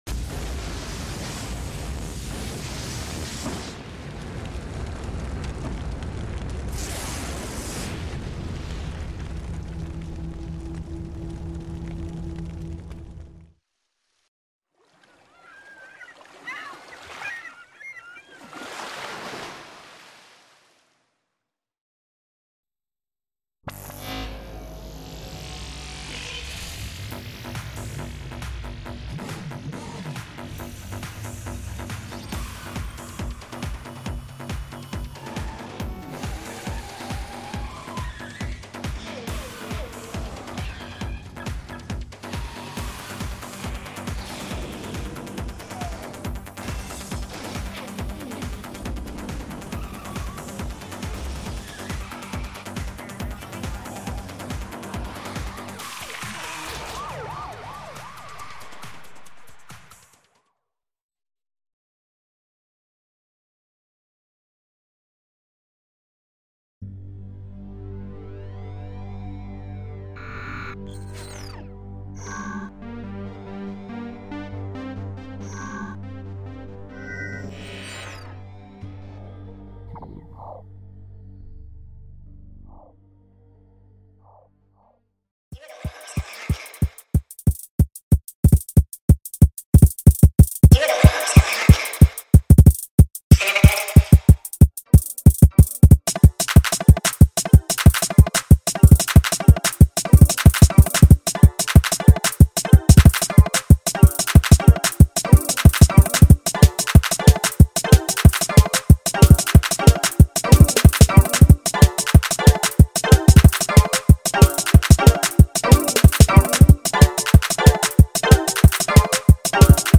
Midnight Club 2 All Vehicles sound effects free download